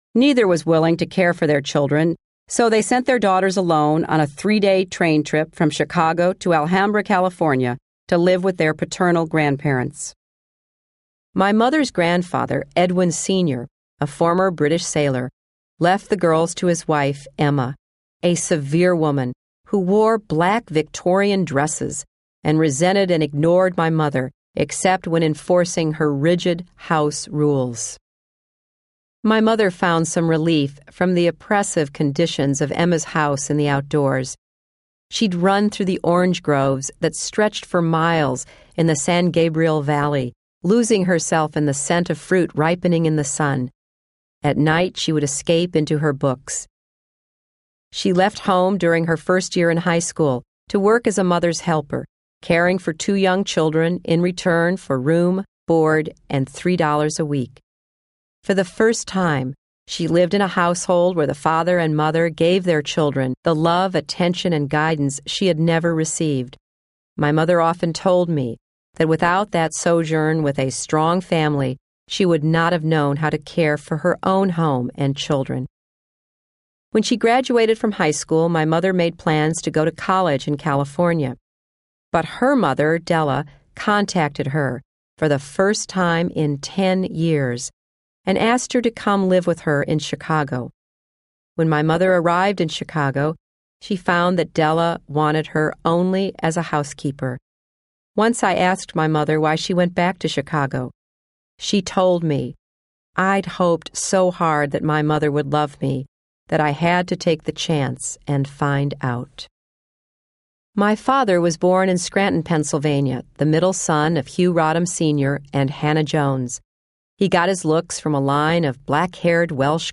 希拉里有声自传Hillary Rodham Clinton03 听力文件下载—在线英语听力室